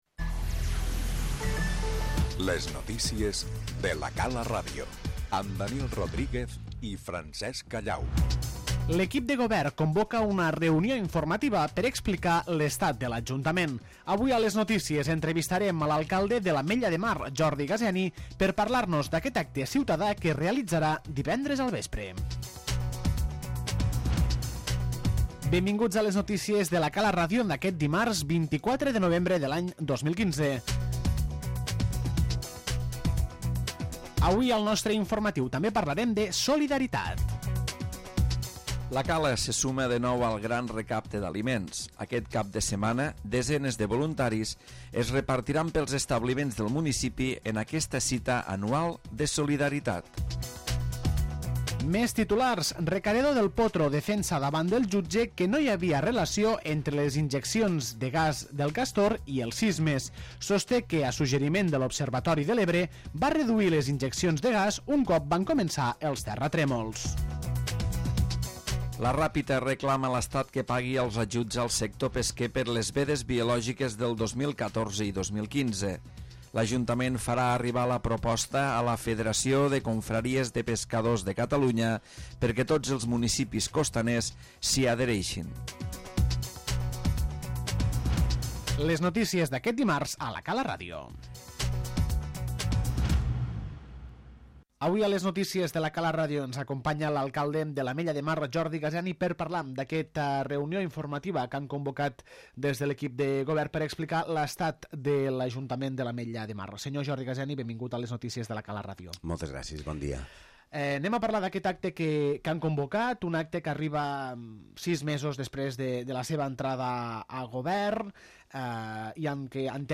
En l'informatiu d'aquest dimarts entrevistem a l'alcalde, Jordi Gaseni, per conèixer els detalls de la reunió informativa per explicar l'estat de l'Ajuntament, i també parlem del Gran Recapte d'Aliments d'aquest cap de setmana.